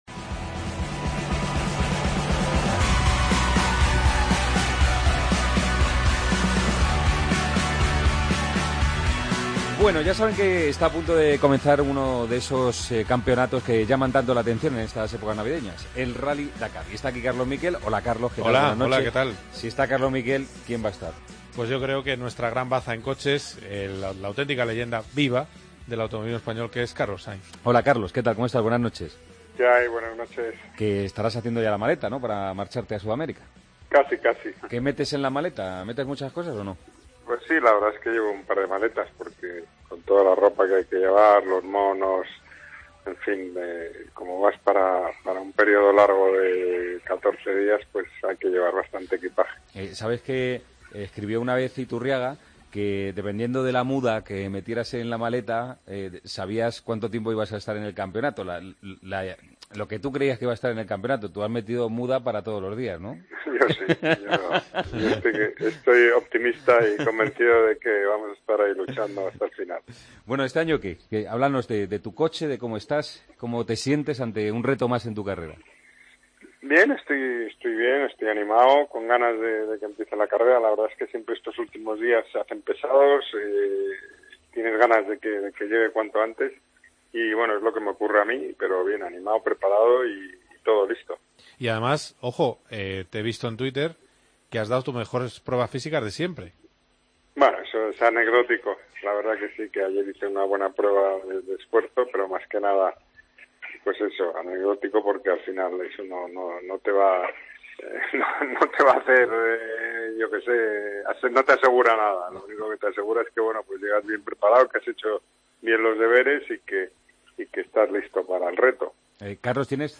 Hablamos con Carlos Sainz antes del inicio del Dakar 2017 en el que opta a la victoria en coches.